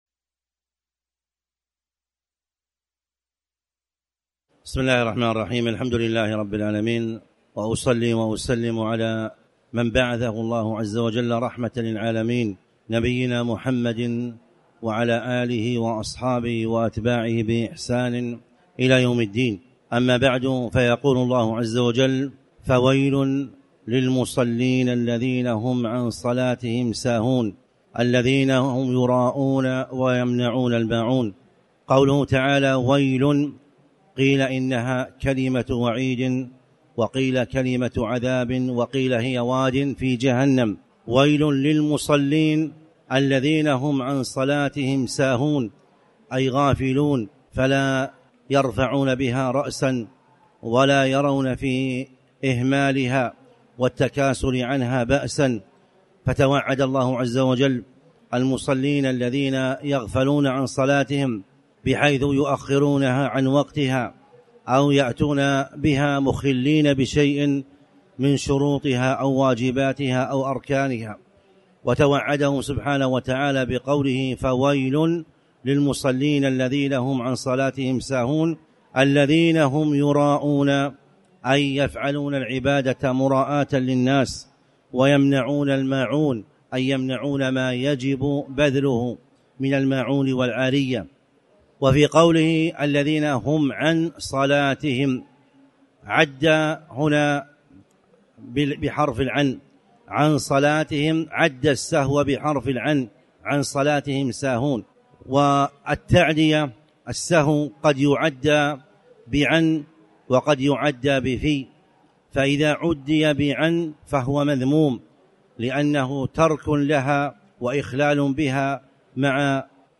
تاريخ النشر ١٧ صفر ١٤٣٨ هـ المكان: المسجد الحرام الشيخ